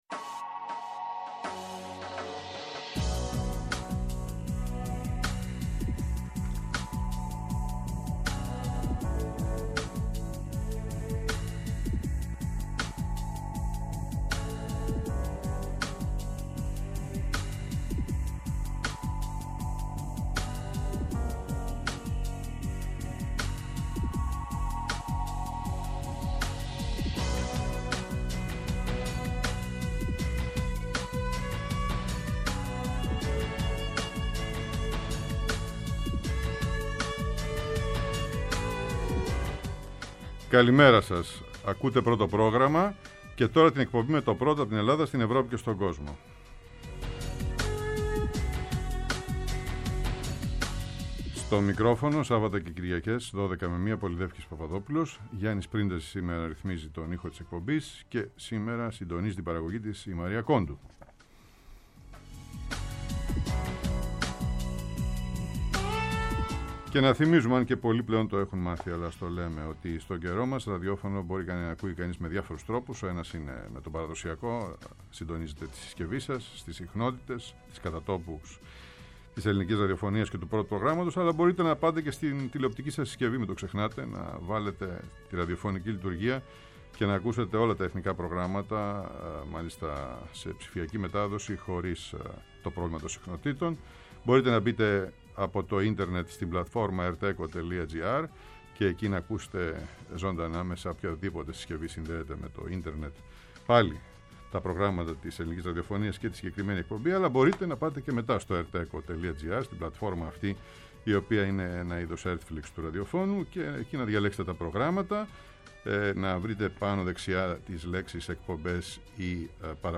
Η παραπομπή Μενέντεζ σε δίκη και η απομάκρυνσή του από την κρίσιμη προεδρία της Επιτροπής Εξωτερικών Υποθέσεων της αμερικανικής Γερουσίας, οι συνέπειες αυτής της εξέλιξης για τα συμφέροντα της Ελλάδας στο Κογκρέσο και την Ουάσιγκτον, καθώς και ο ρόλος του εν γένει ελληνοαμερικανικού λόμπι. Καλεσμένος, ο Καθηγητής Διεθνών Σχέσεων στο Πάντειο Πανεπιστήμιο και γνώστης της αμερικανικής πολιτικής, Κωνσταντίνος Αρβανιτόπουλος.